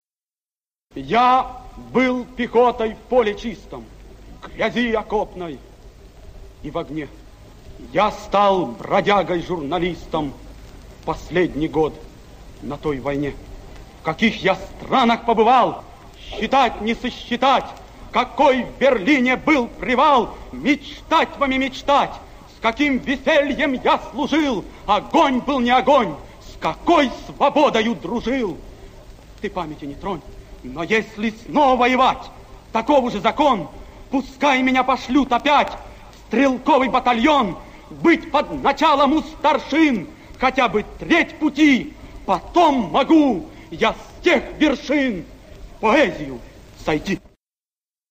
1. «Семён Гудзенко – Я был пехотой в поле чистом (читает автор)» /